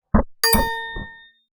UI_SFX_Pack_61_16.wav